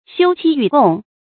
xiū qī yǔ gòng
休戚与共发音
成语正音 与，不能读作“yú”或“yù”。